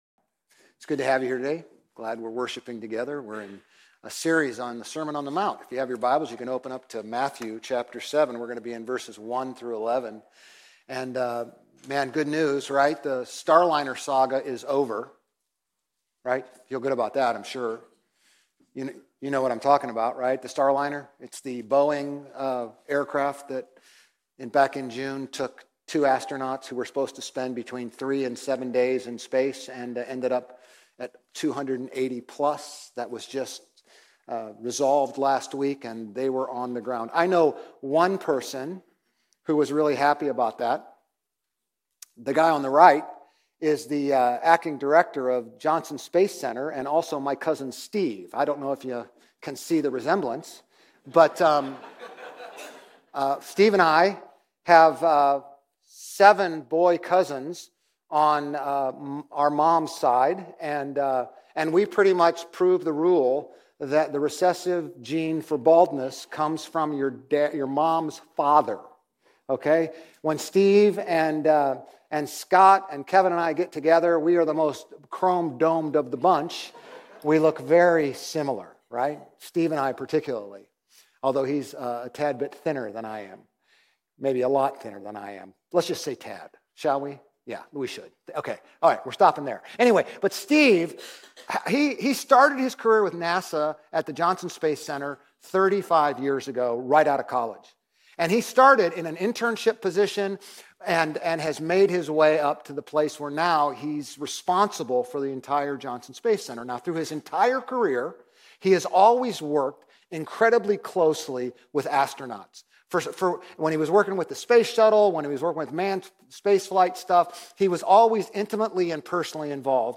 Grace Community Church Old Jacksonville Campus Sermons 3_23 Old Jacksonville Campus Mar 24 2025 | 00:34:54 1x 00:00 / 00:34:54 Subscribe Share RSS Feed Share Link Embed